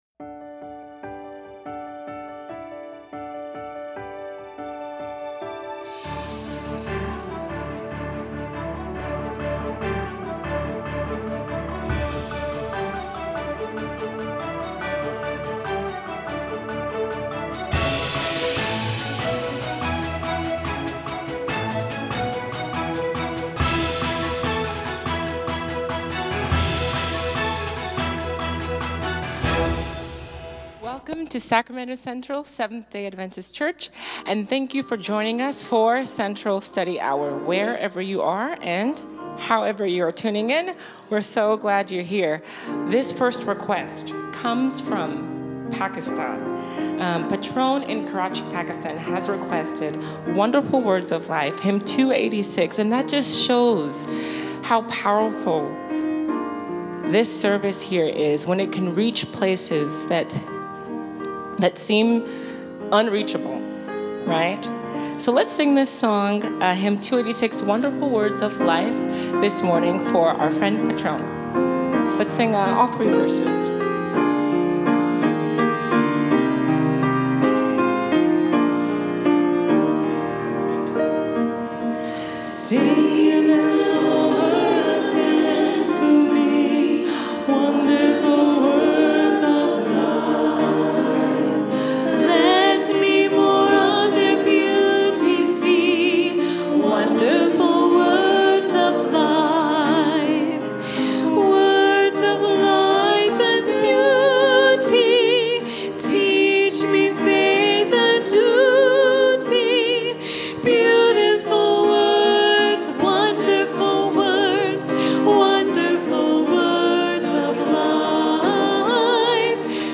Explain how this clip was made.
Central Study Hour – Sacramento Central Seventh-day Adventist Church